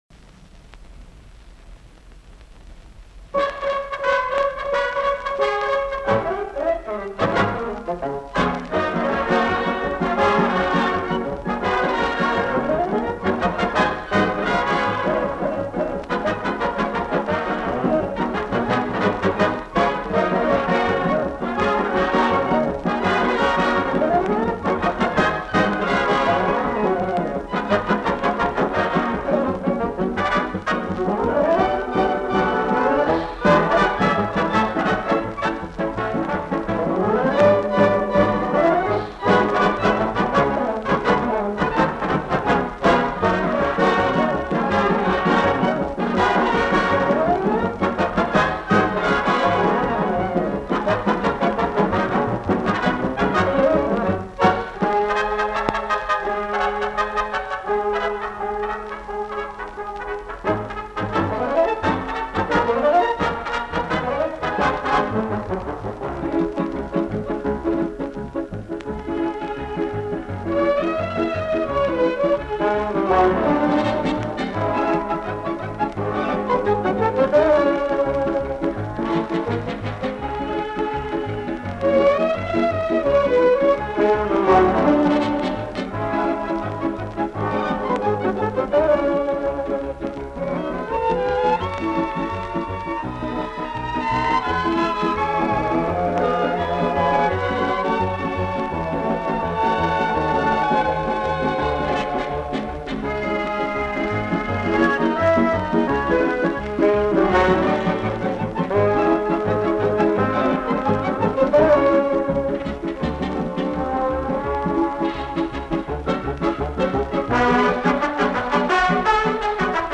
быстрый танец